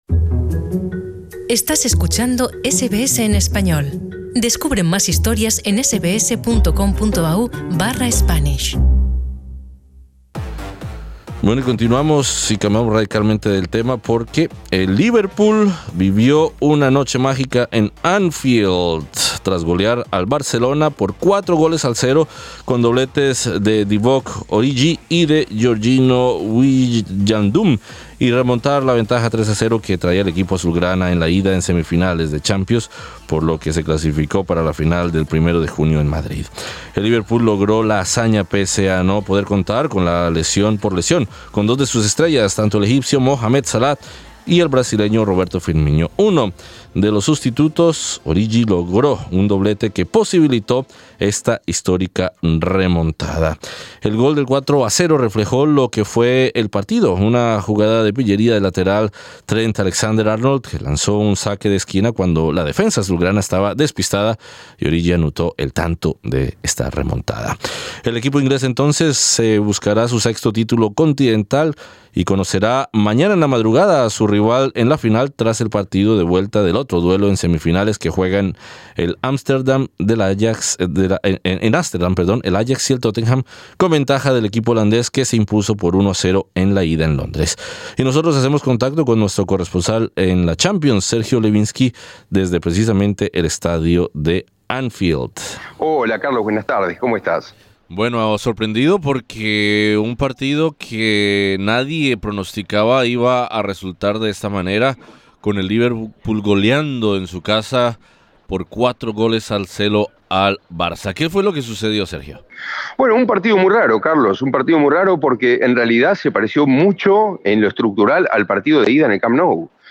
desde el estadio de Anfield.